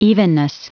Prononciation du mot evenness en anglais (fichier audio)
Prononciation du mot : evenness